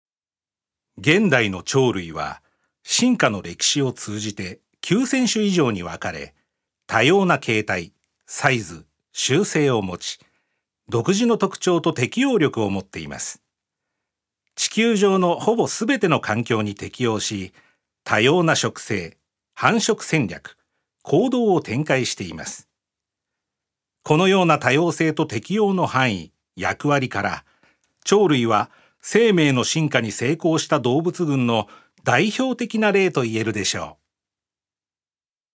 所属：男性タレント
音声サンプル
ナレーション１